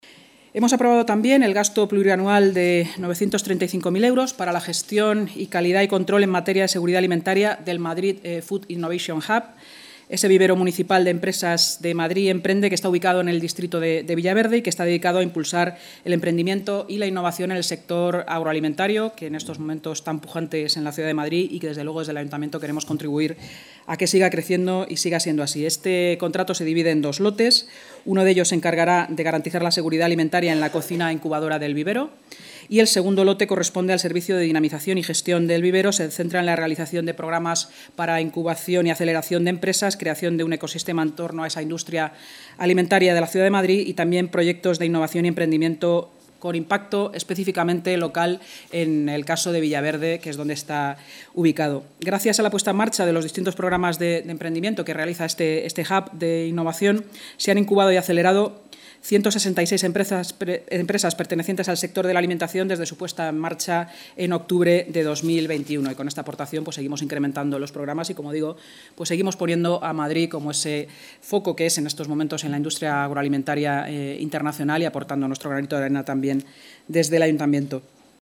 Nueva ventana:Declaraciones de la vicealcaldesa, Inma Sanz, sobre la inversión en materia de seguridad alimentaria de Madrid Food Innovation Hub (MFIH)